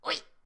capri_whee3.ogg